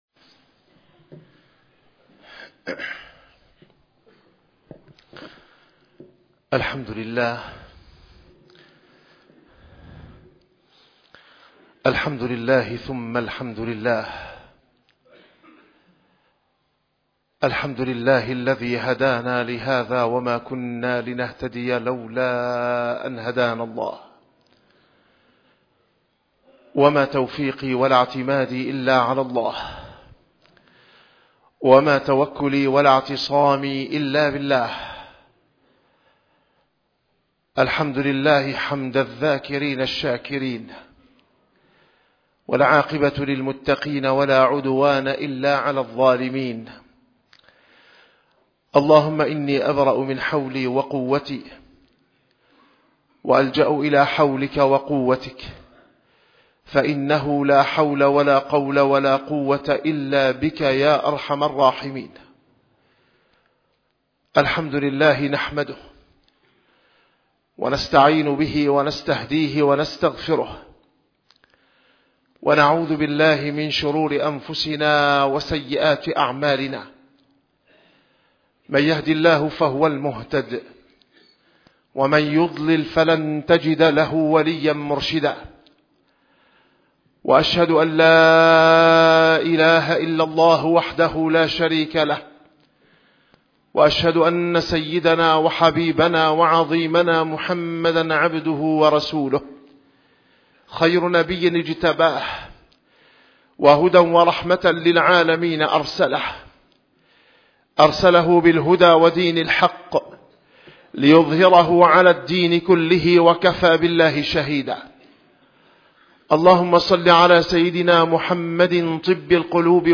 - الخطب - الهجرة النبوية (هجرة الأصحاب الكرام ومنهجية عمر الفاروق)